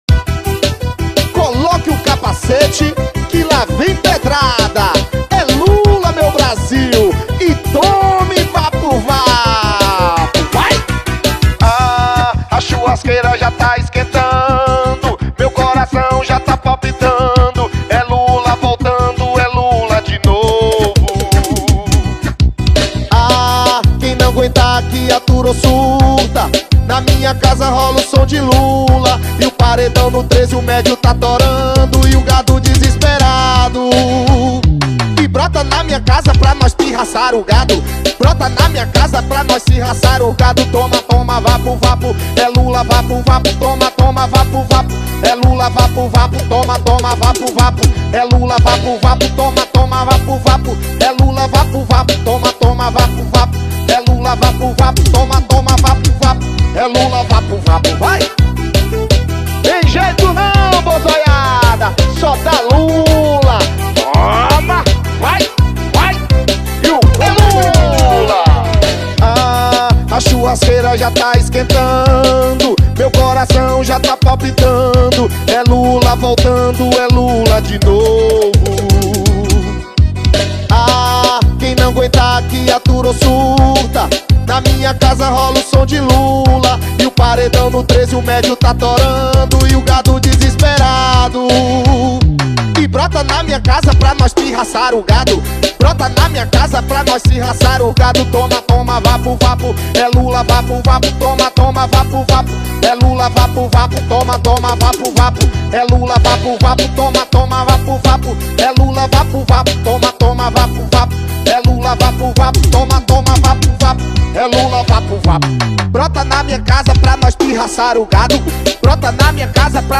Gênero documentaldocumento sonoro
Música : temático